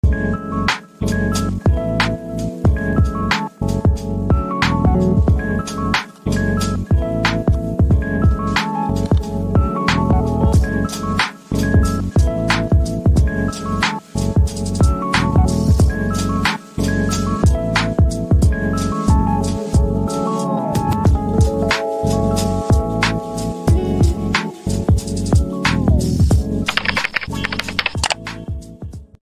MEPS Mp3 Sound Effect Best 5-6" FPV Motor? MEPS SZ2408 Unboxing & Flight Test!